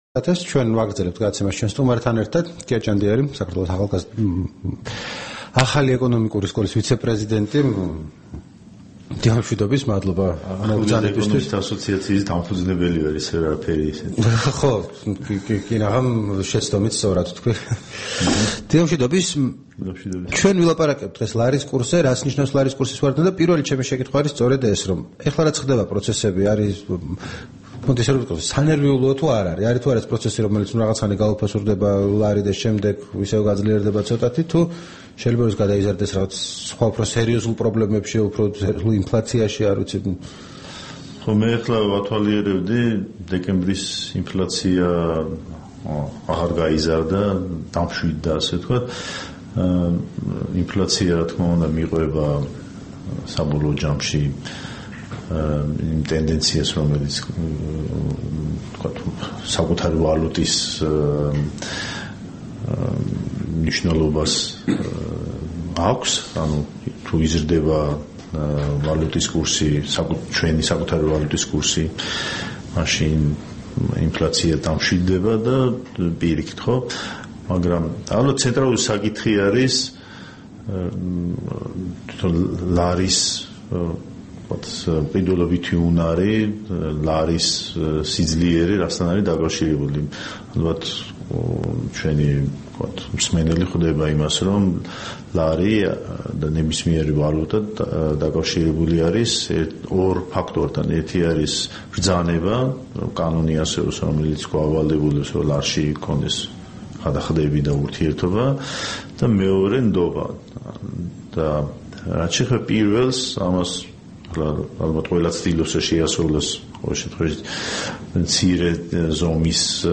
რადიო თავისუფლების თბილისის სტუდიაში სტუმრად იყო "ახალი ეკონომიკური სკოლა